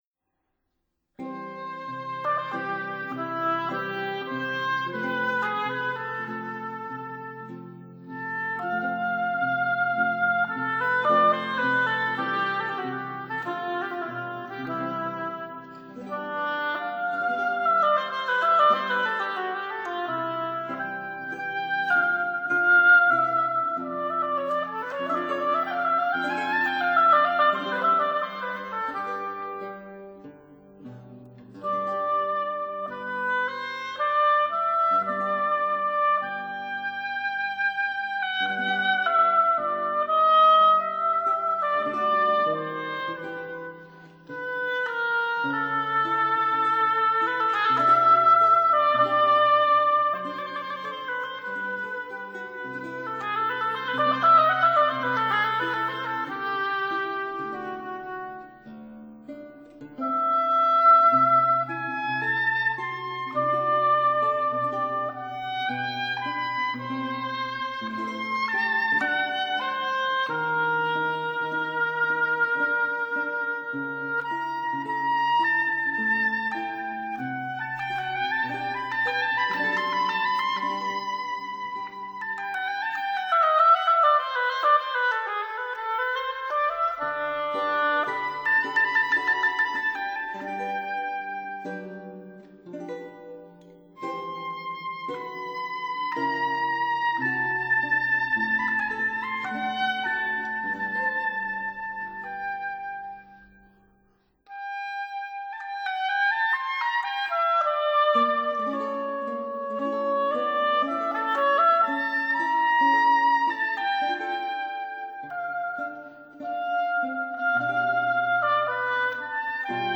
Modern Oboe
Bassoon